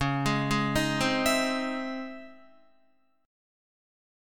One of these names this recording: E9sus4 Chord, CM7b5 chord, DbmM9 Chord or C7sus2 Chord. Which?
DbmM9 Chord